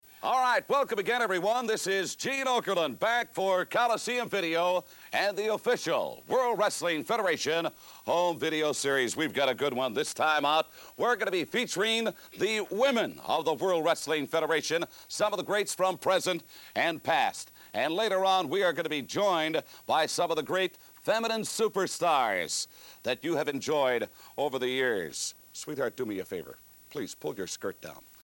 Hosted by “Mean Gene” Okerlund on the legendary Prime Time Wrestling set, we’re going to see all the greatest females in the history of the company.